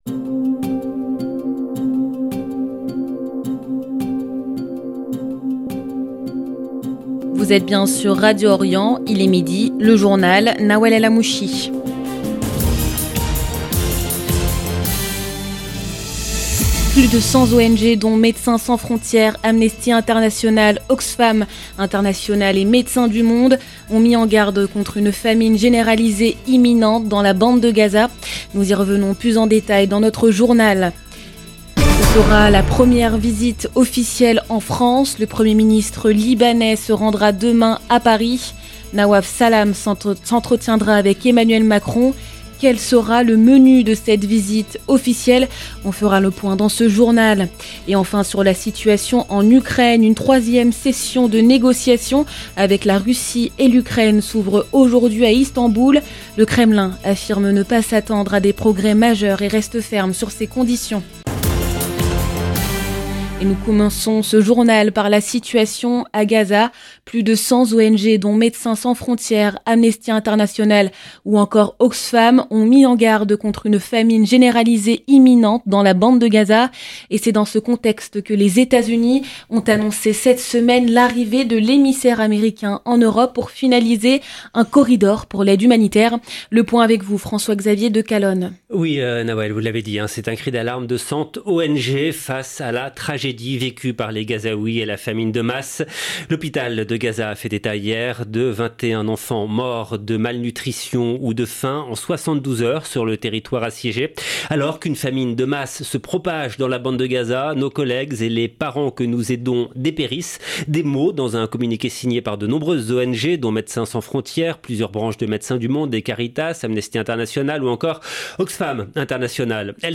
Journal de midi du 23 juillet 2025